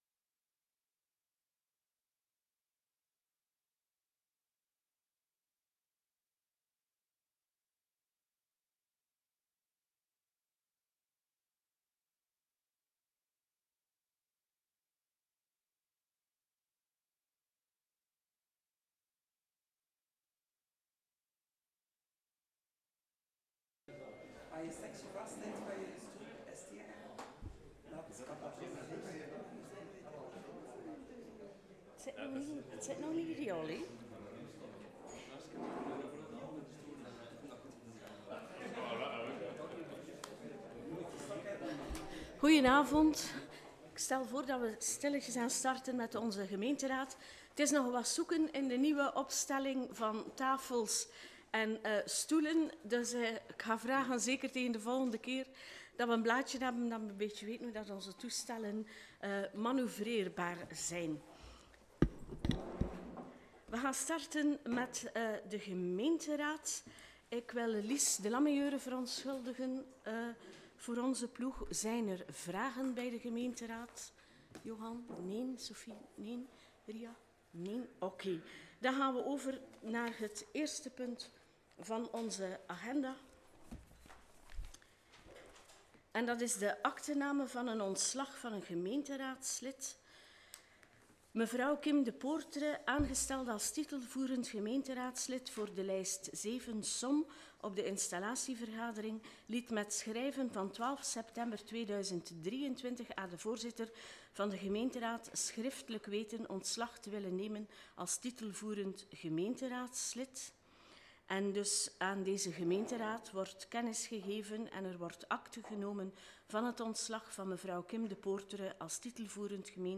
Vergadering 25 september 2023